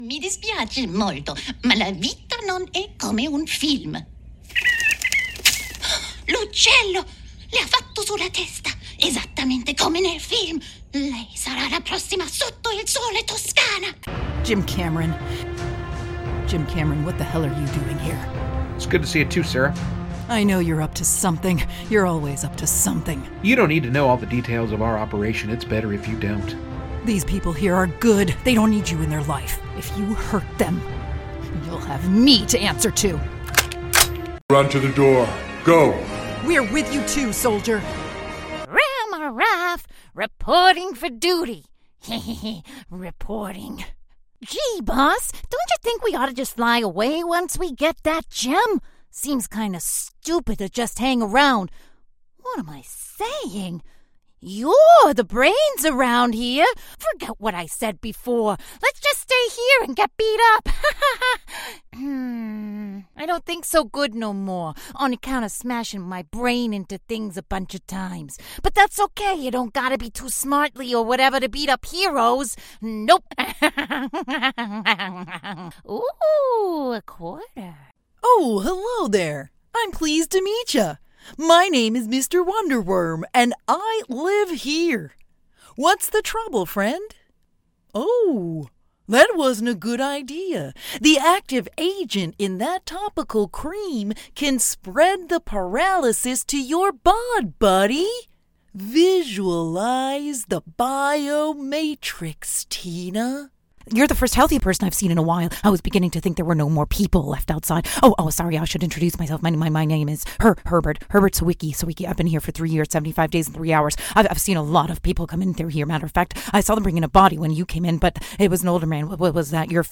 PROFESSIONAL HOME STUDIO:
Neumann TLM 103
The Sophisticated, International, Clear and Trustworthy Voice You’re Looking For
ANIMATION